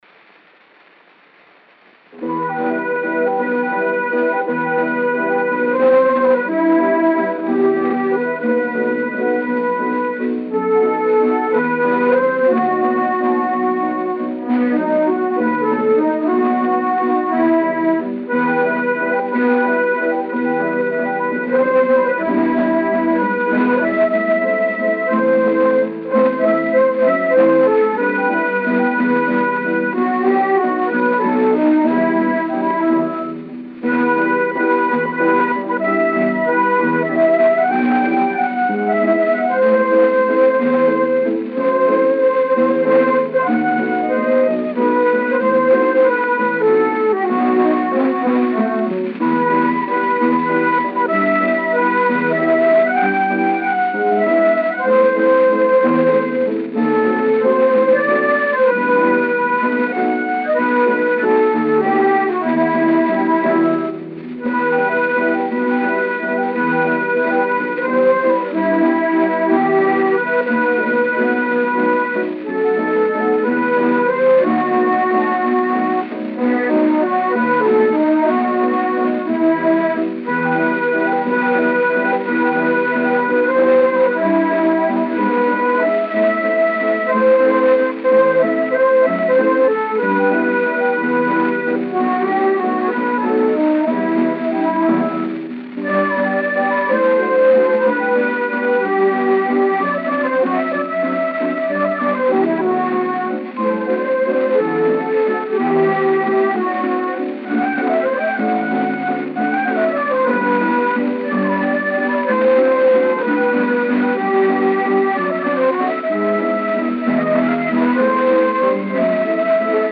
O gênero musical foi descrito como "Valsa".